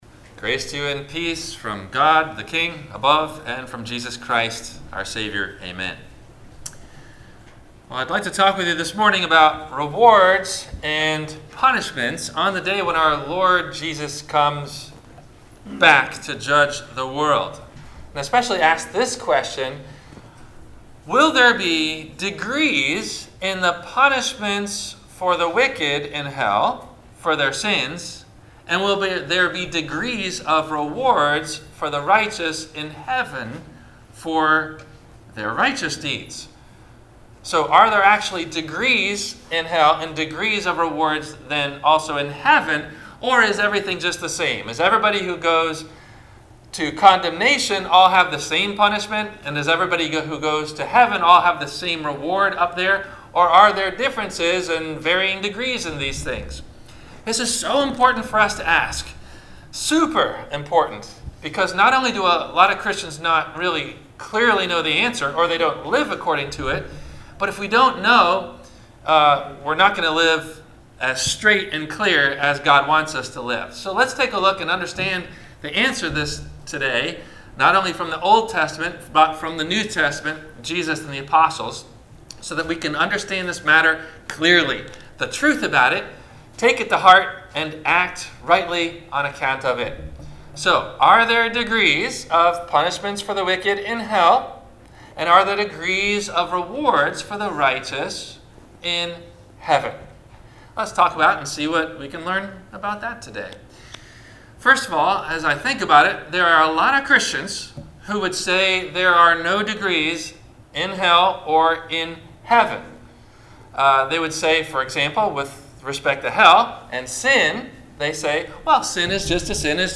- Sermon - August 11 2019 - Christ Lutheran Cape Canaveral